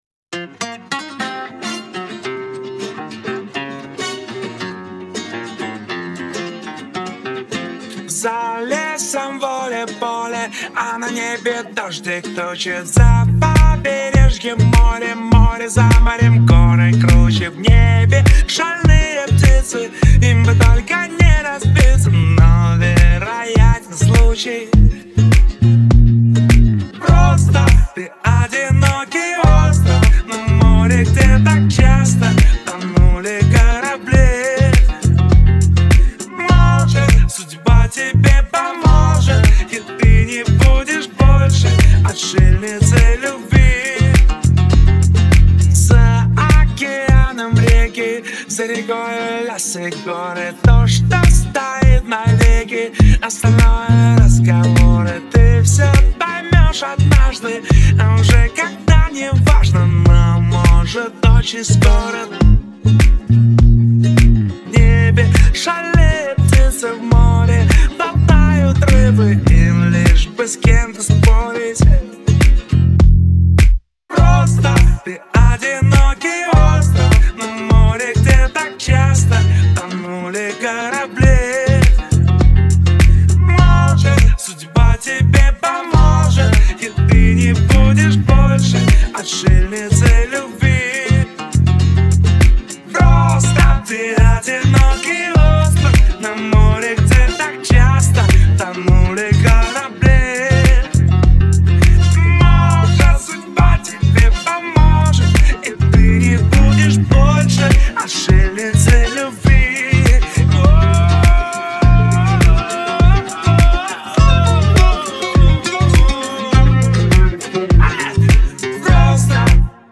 Скачать музыку / Музон / Новые ремиксы 2024